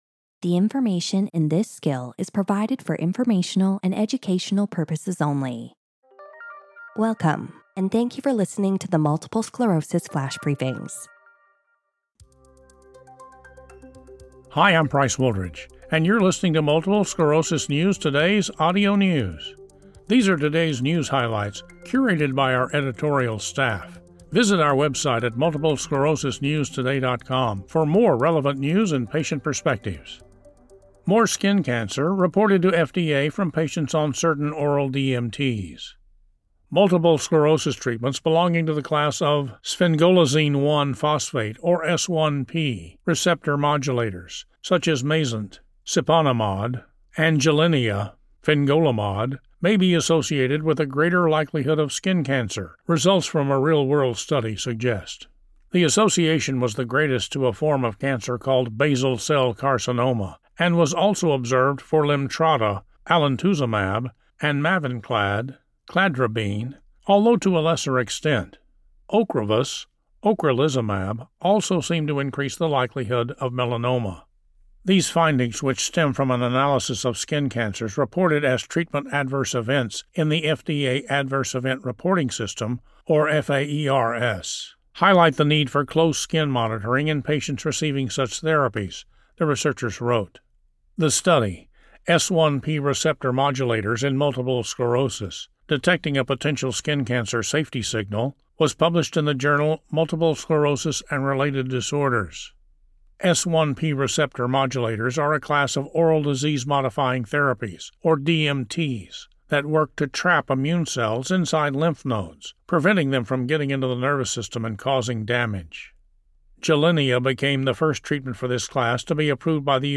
MS News & Perspectives